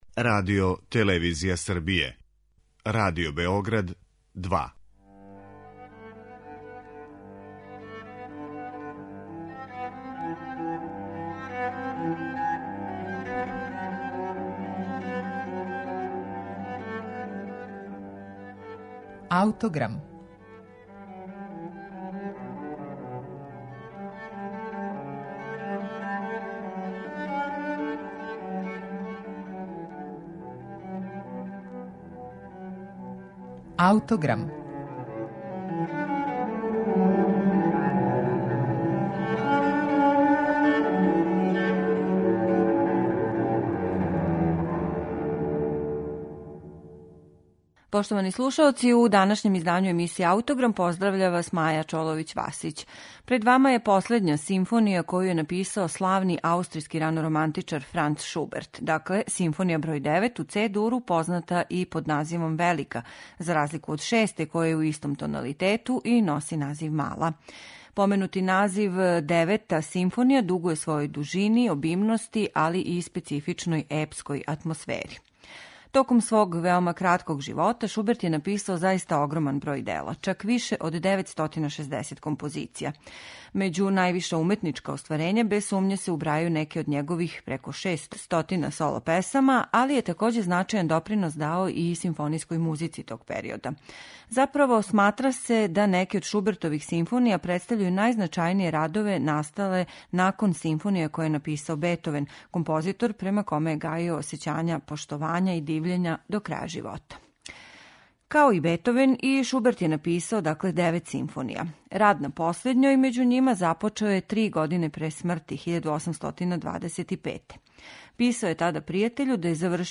Данас је слушамо у извођењу Бостонског симфонијског оркестра, којим диригује Шарл Минш.